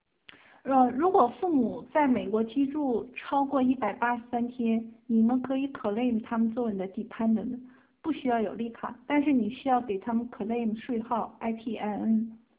这里是上周日（3/8），亚特兰大【房群】举办的微信讲座问答汇集（二）；问题是文字形式，对答是语音形式，点击即可播放。